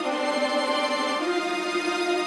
Index of /musicradar/gangster-sting-samples/105bpm Loops
GS_Viols_105-DF.wav